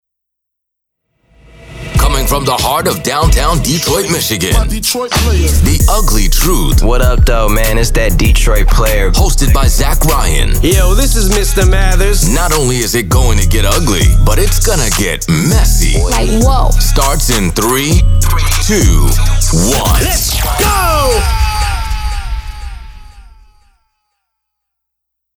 UGLY TRUTH PODCAST INTRO